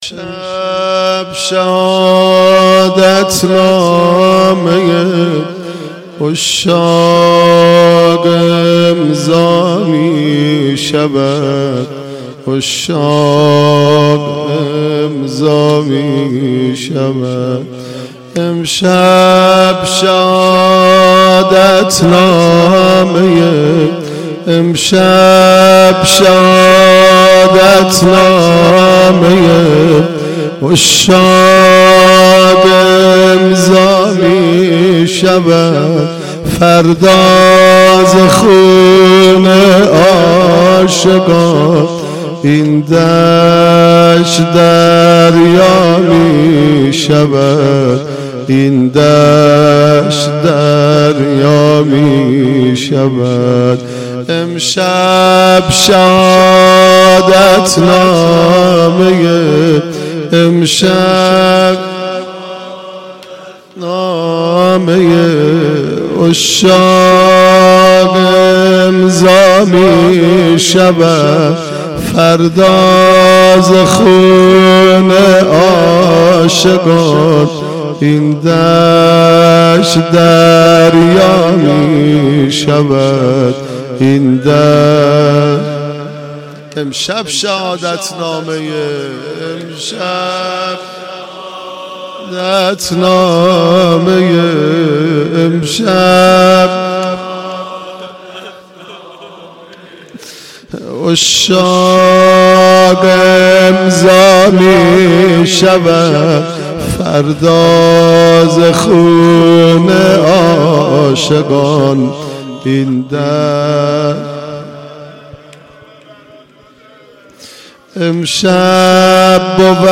صوت/ گلچین مداحی شب عاشورا